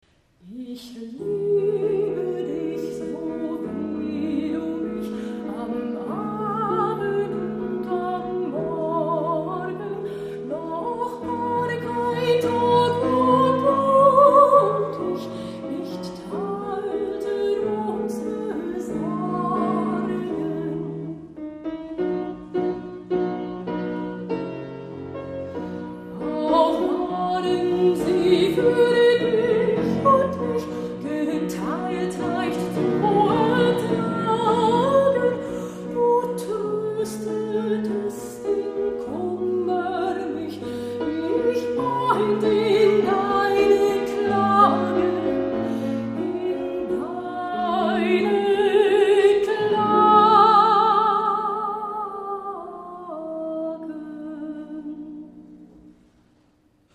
Mezzosopran
Hammerflügel